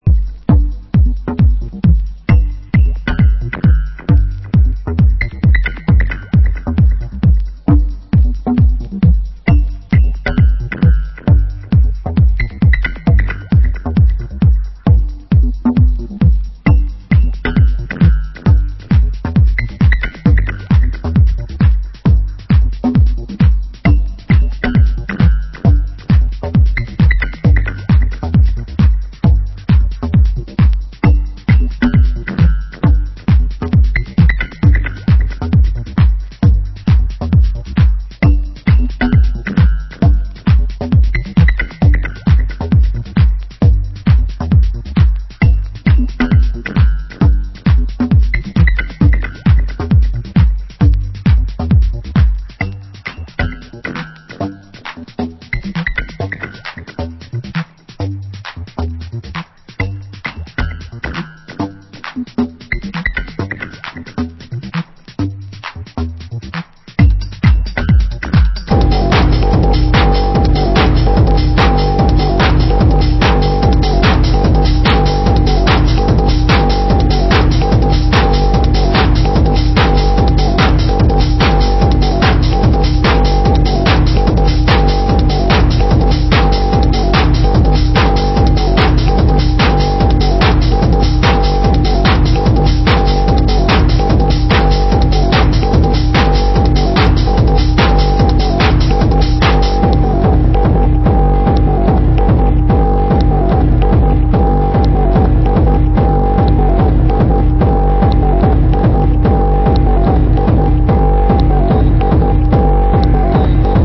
Genre: Techno